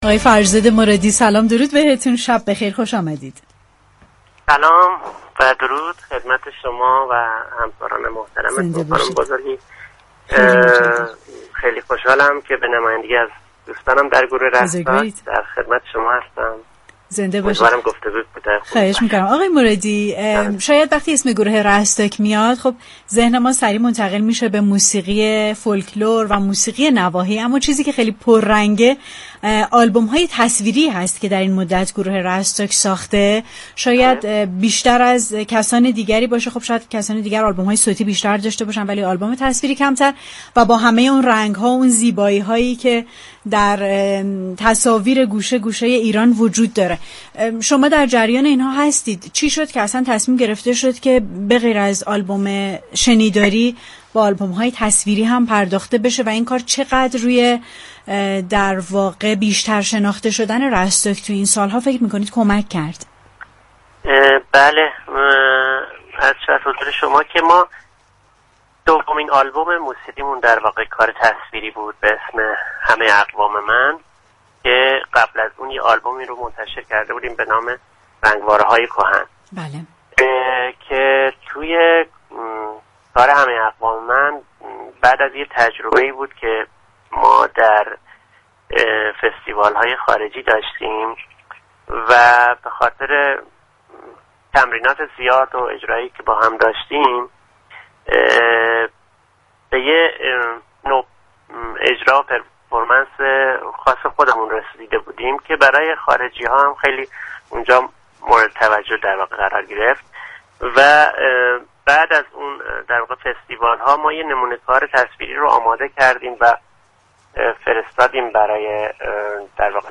مهمان برنامه پشت صحنه رادیو تهران